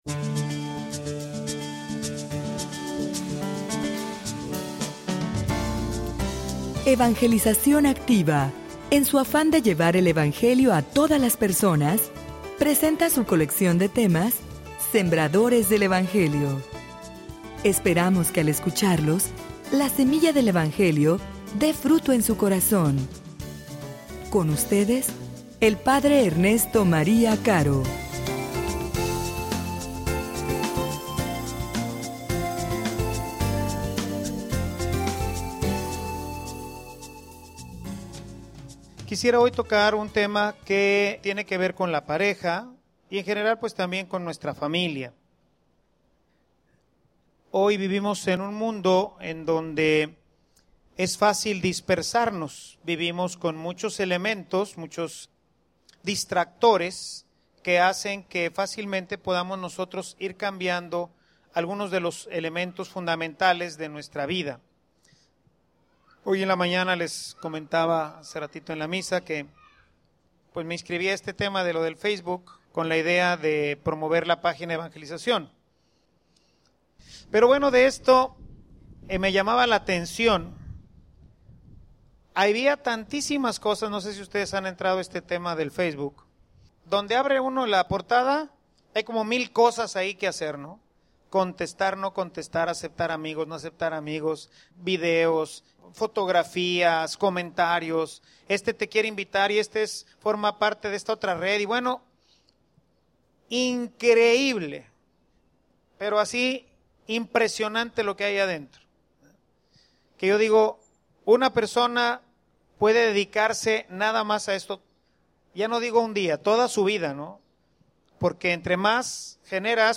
homilia_Eres_radical_o_eres_simpatizante.mp3